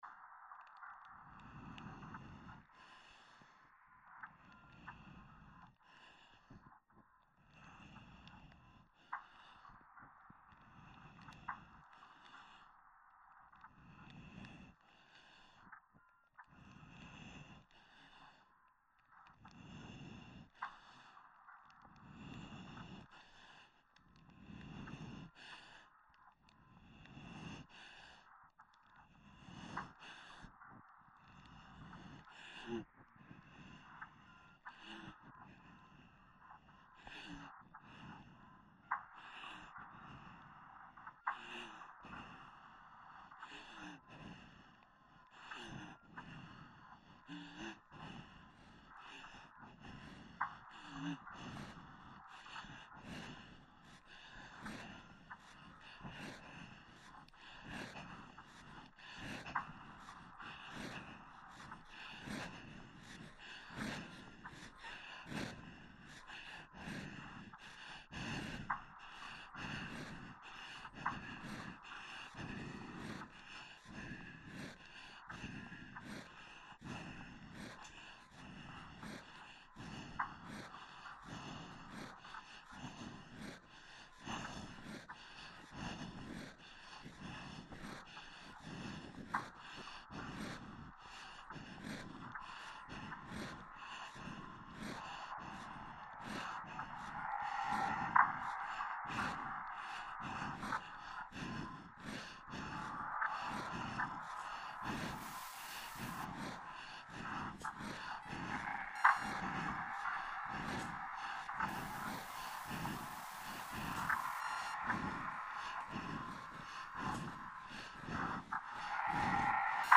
adem
ipex-1breath.mp3